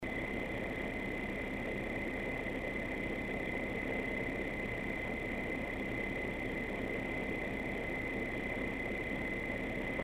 Xigmatek have equipped the HDT-S1284EE heatsink with a 120mm PWM fan set on vibration absorbing rubber posts. At full speed it produces a moderate amount of noise, at low speed it's very quiet.
frostytech acoustic sampling chamber - low speed
standard waveform view of a 10 second recording. click on the headphones icon to listen to an mp3 recording of this heatsink in operation. the fan is rotating at est. 800 rpm.
Xigmatek HDT-S1284EE low 37.2 dB Intel/AMD